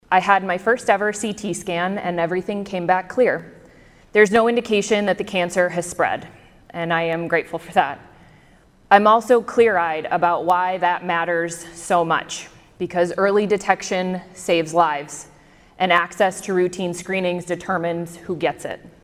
DREY ANNOUNCED HER RECENT DIAGNOSIS MONDAY IN REMARKS DELIVERED ON THE FLOOR OF THE IOWA SENATE.